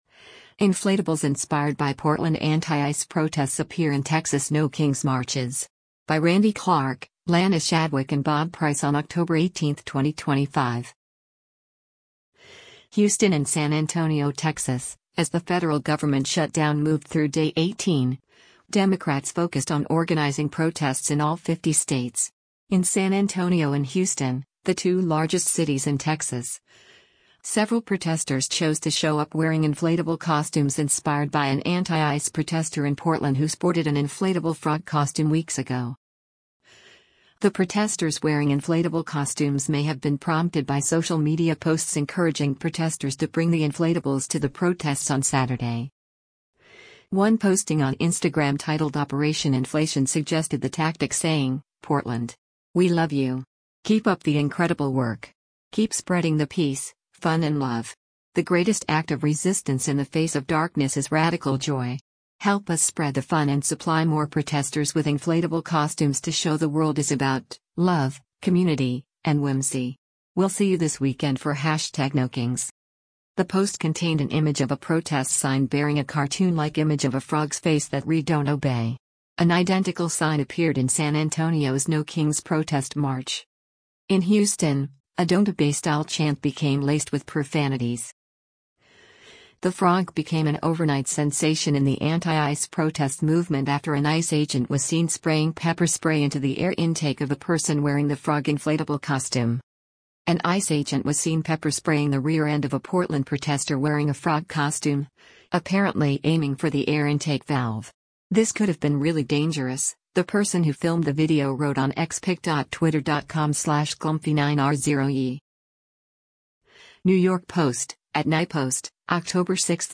A mix of inflatable characters march in Houston and San Antonio No-Kings Protests